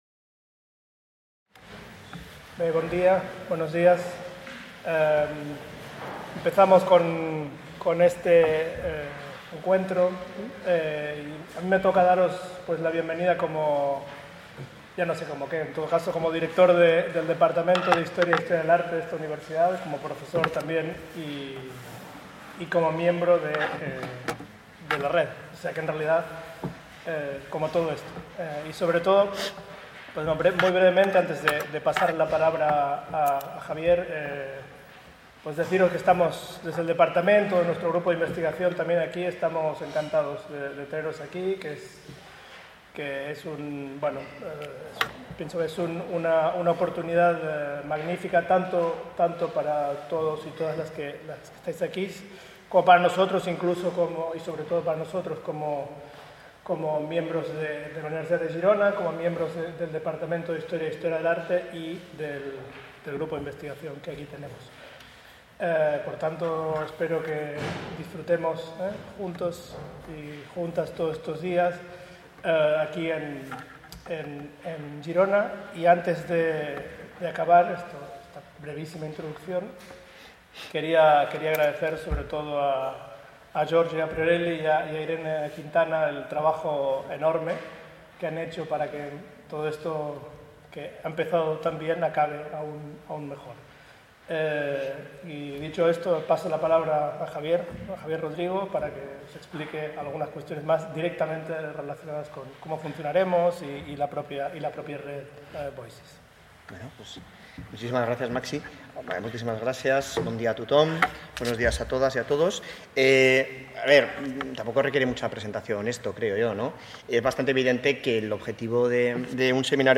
Presentació